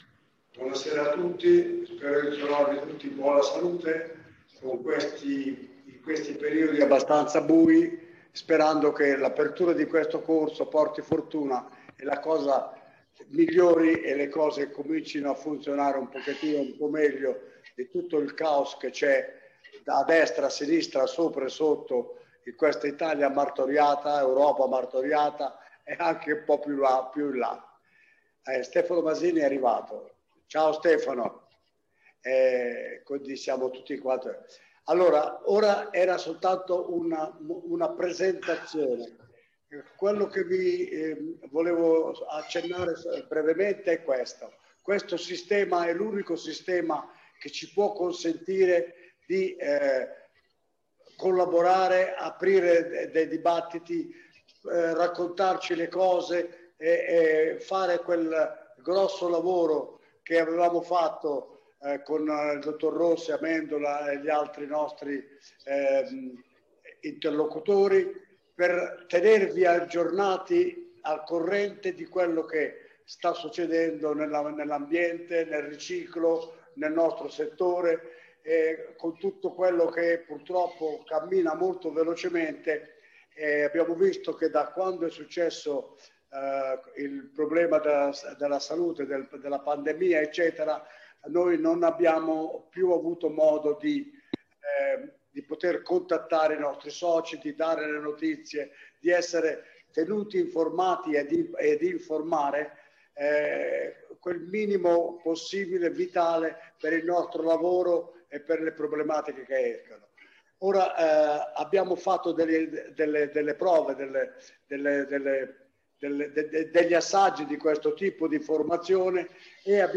webinar-presentazione-corsi-2021.mp3